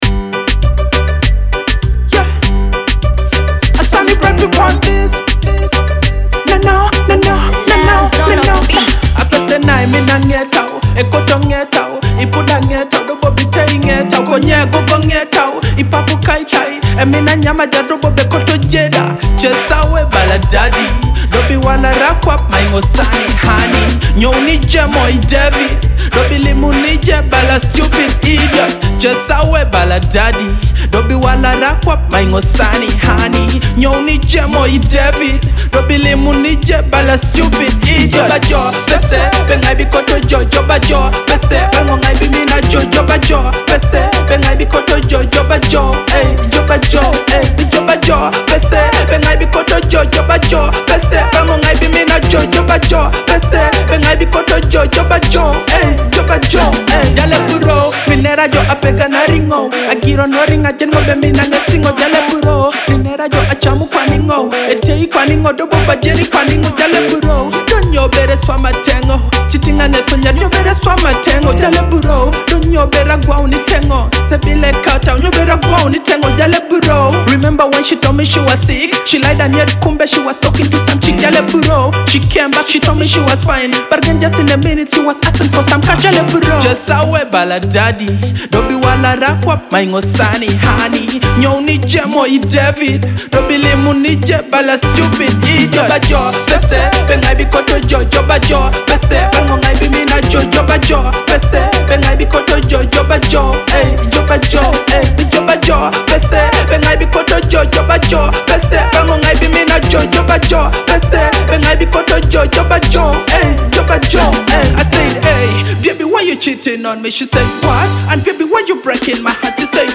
a hot Ugandan dancehall track.